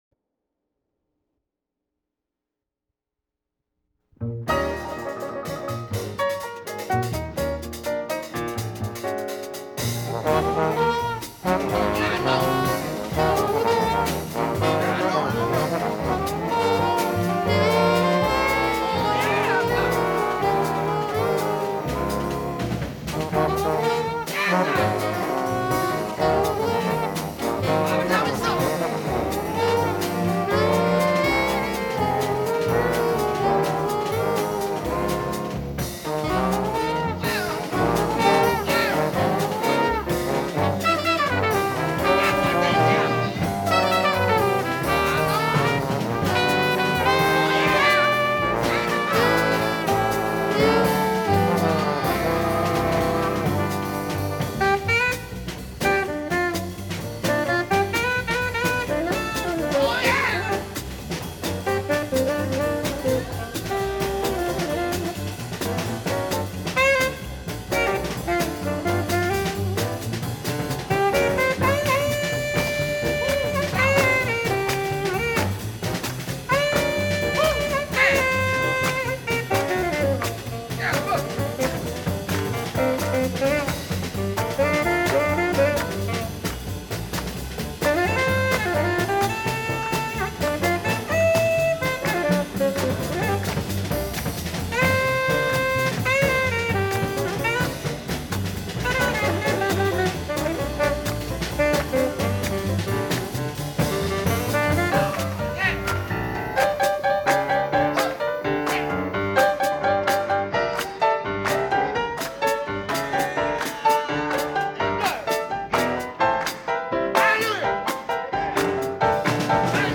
Bass
Drums
Piano
Reeds
Trombone
Trumpet
Vibraphone